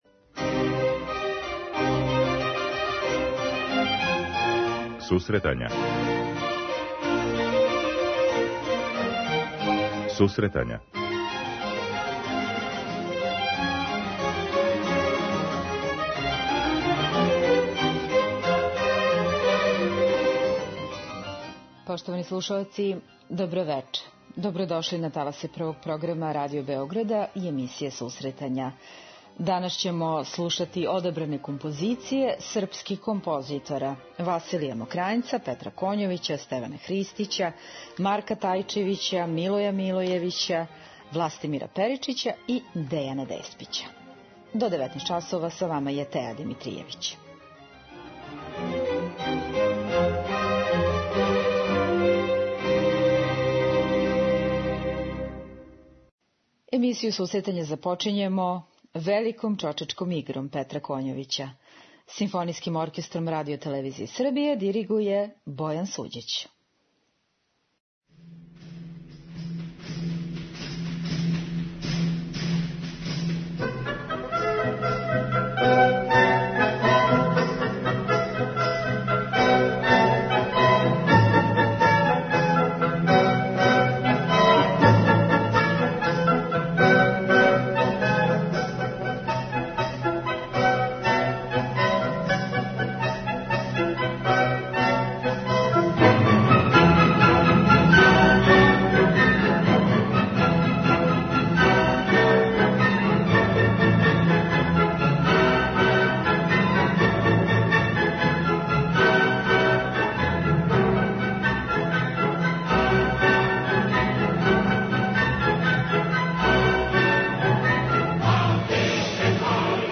преузми : 10.21 MB Сусретања Autor: Музичка редакција Емисија за оне који воле уметничку музику.